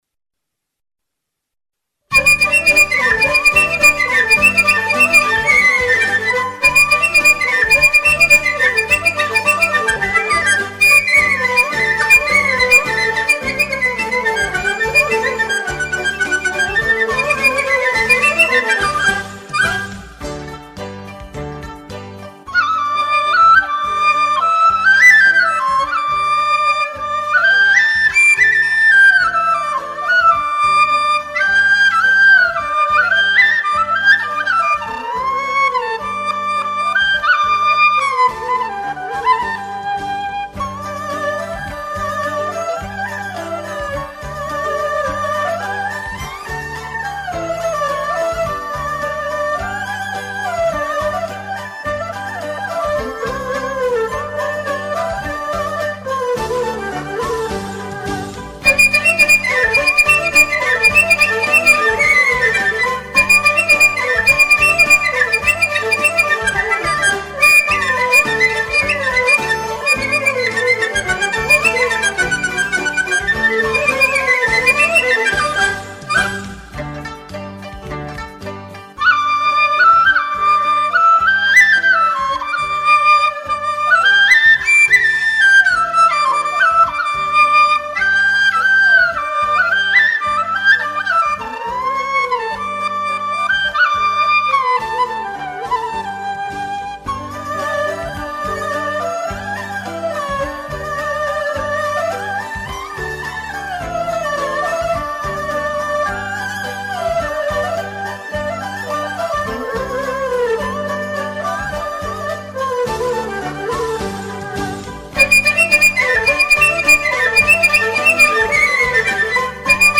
今年共有47个贴（有3个联奏），其中笛子曲47首、葫芦丝曲3首，合计50首乐曲。